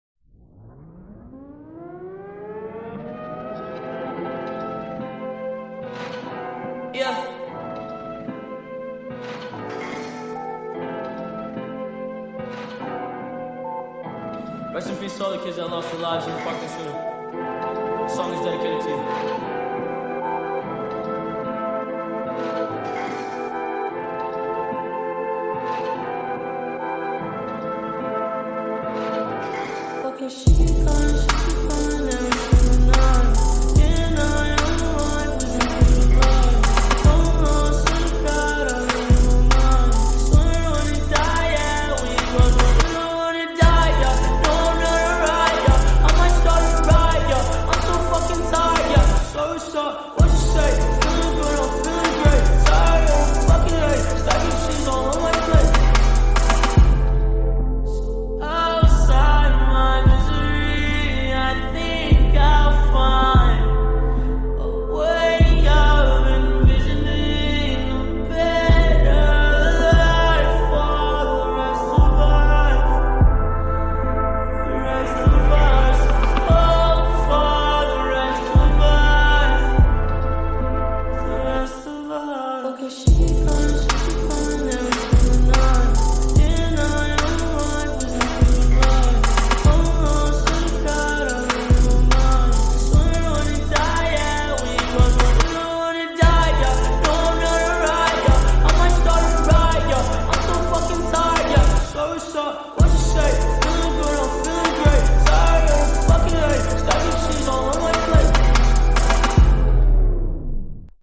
ریمیکس 8 بعدی